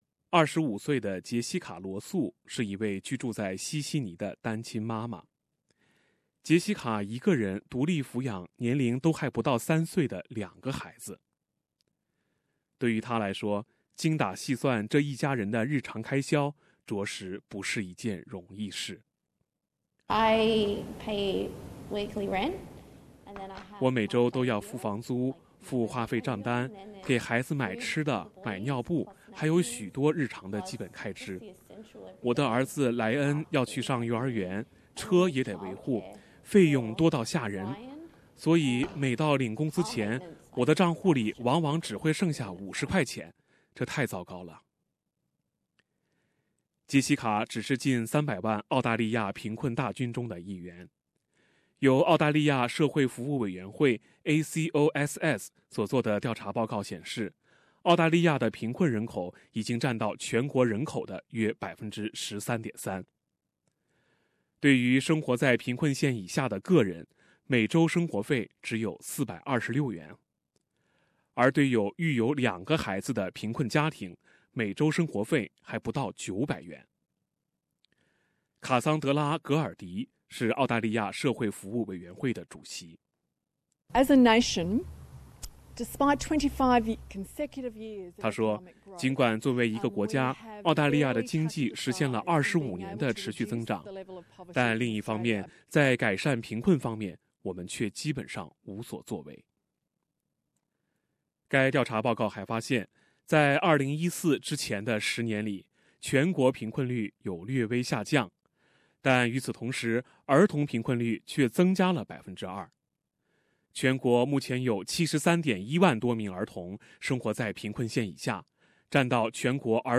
Source: (by AAP) SBS 普通话电台 View Podcast Series Follow and Subscribe Apple Podcasts YouTube Spotify Download (2.5MB) Download the SBS Audio app Available on iOS and Android 10月17日是联合国消除贫困国际日。